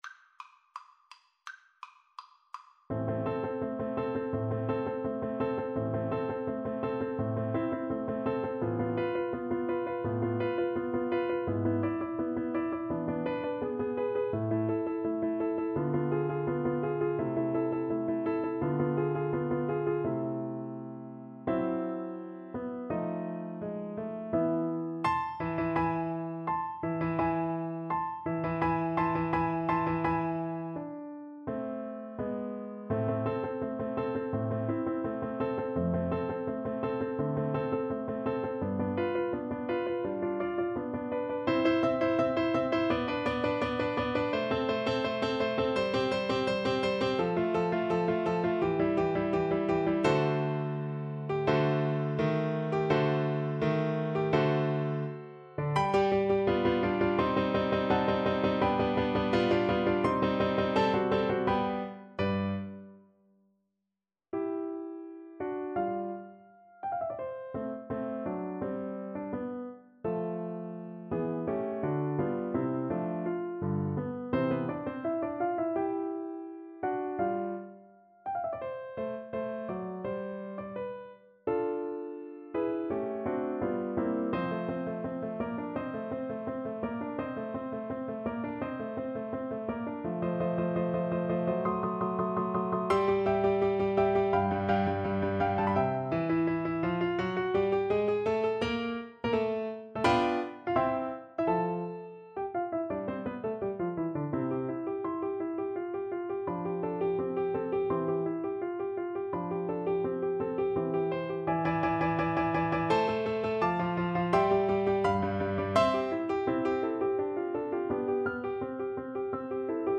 Allegro Molto =c.168 (View more music marked Allegro)
4/4 (View more 4/4 Music)